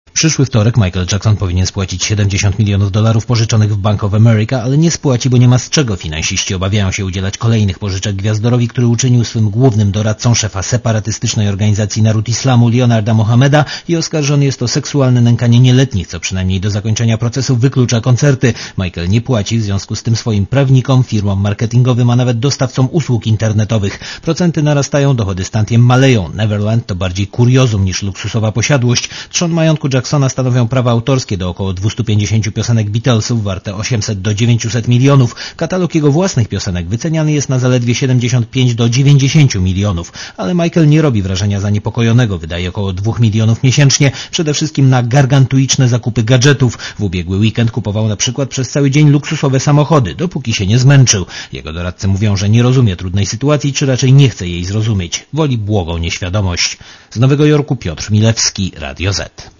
Korespondencja z USA